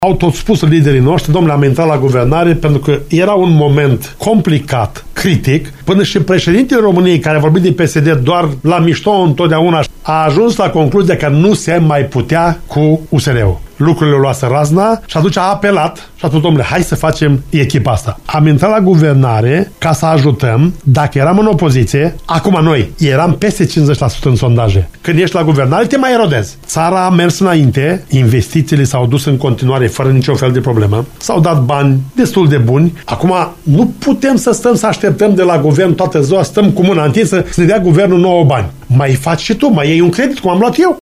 În calitate de membru social-democrat, el a declarat postului nostru că autoritățile locale se pot dezvolta armonios numai dacă accesează fonduri europene, deoarece “nu trebuie să stea cu mâna întinsă după bani la Guvern”.